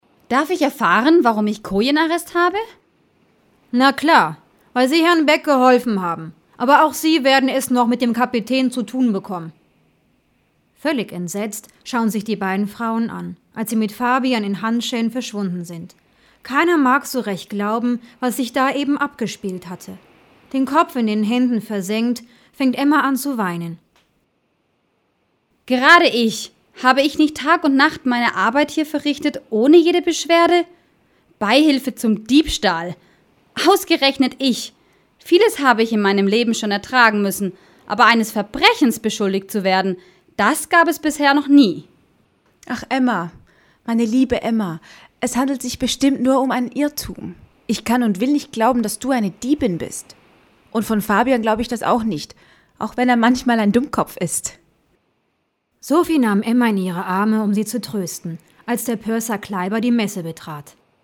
Download Steckbrief Download Hörprobe: ©2005-2014 Die Seefrau